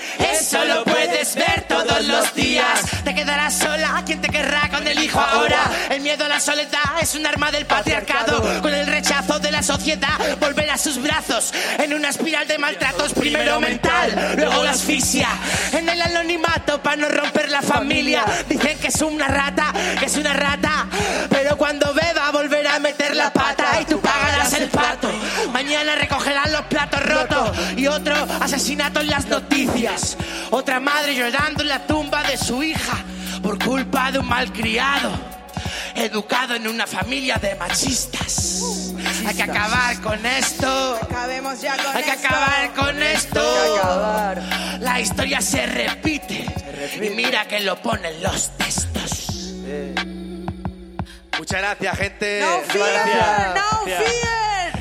El alcalde de Antequera, Manolo Barón, y el teniente de alcalde delegado de Igualdad, Alberto Arana, han presidido en el mediodía de hoy jueves el acto institucional conmemorativo del Día Internacional de la Eliminación de la Violencia contra la Mujer que, desde que fuera instaurado por la ONU, se celebra anualmente cada 25 de noviembre.
Cortes de voz